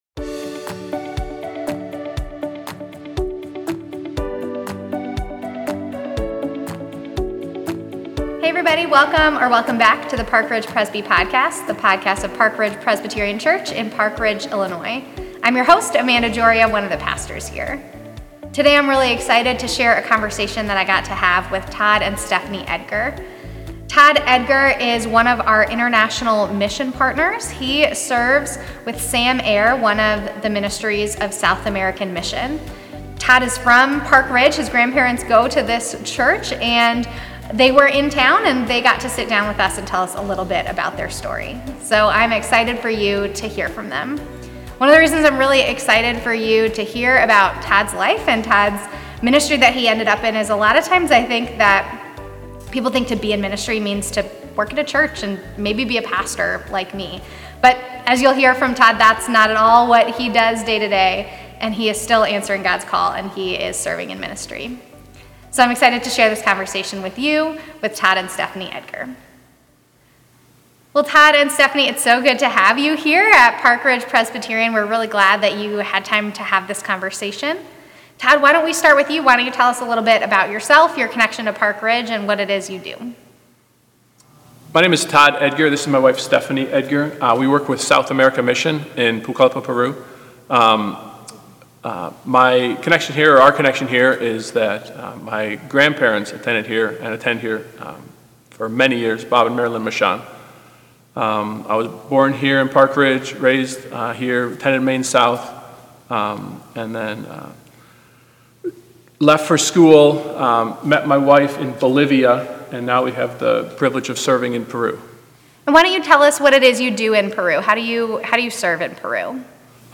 We hope you enjoy this conversation.